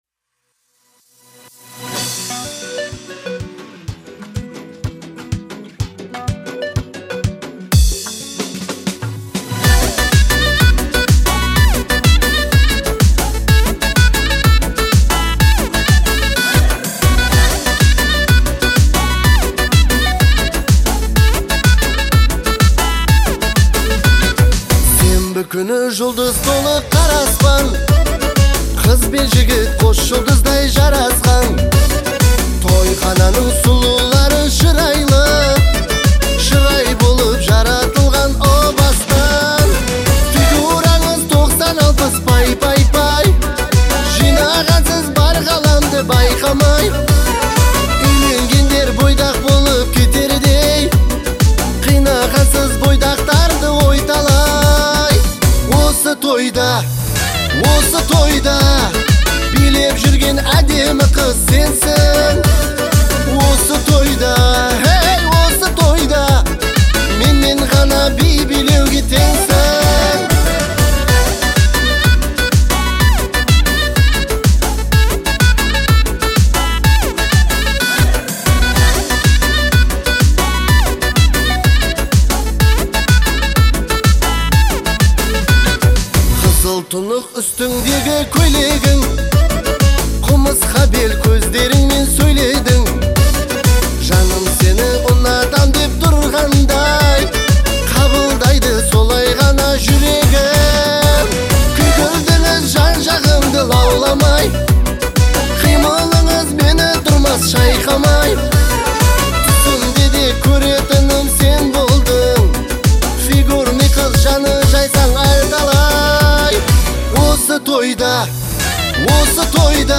яркая композиция в жанре узбекской поп-музыки